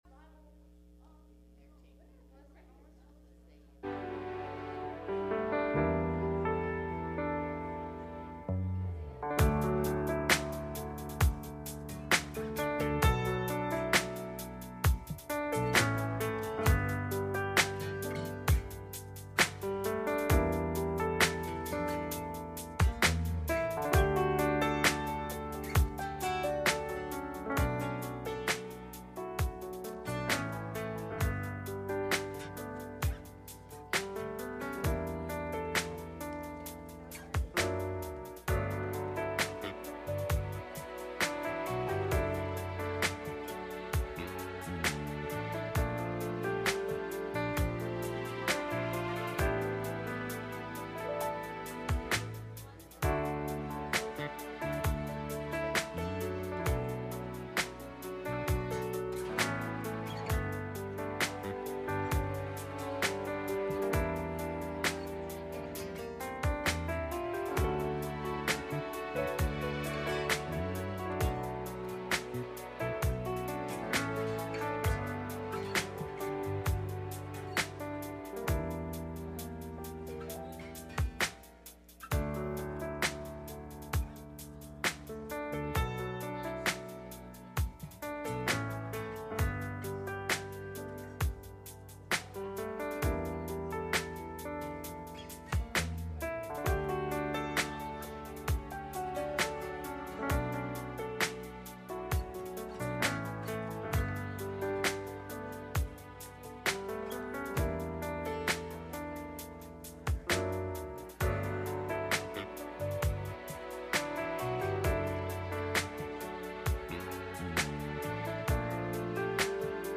Wednesday Night Service
Midweek Meeting